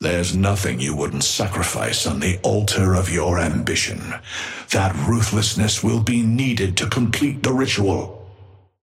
Amber Hand voice line - There's nothing you wouldn't sacrifice on the altar of your ambition.
Patron_male_ally_gigawatt_start_04.mp3